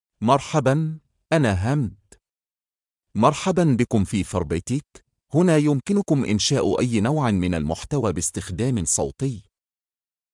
MaleArabic (United Arab Emirates)
HamdanMale Arabic AI voice
Hamdan is a male AI voice for Arabic (United Arab Emirates).
Voice sample
Listen to Hamdan's male Arabic voice.
Male
Hamdan delivers clear pronunciation with authentic United Arab Emirates Arabic intonation, making your content sound professionally produced.